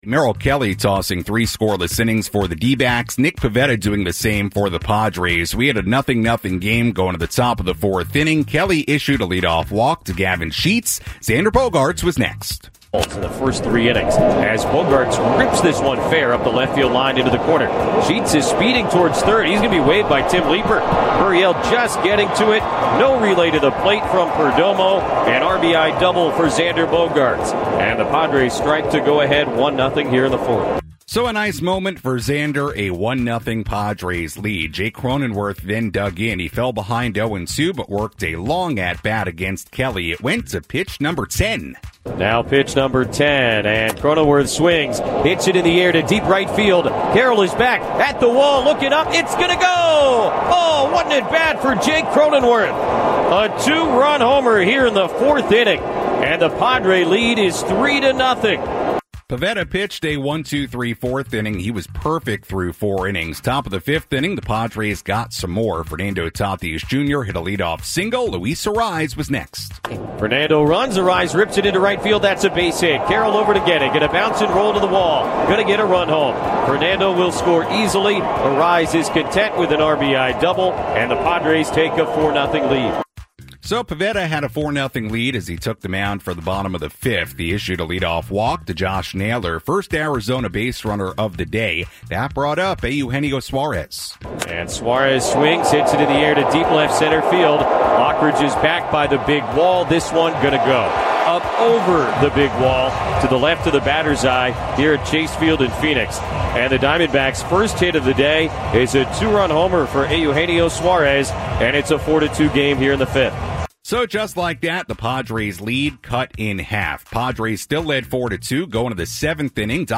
along with highlights from the radio broadcast.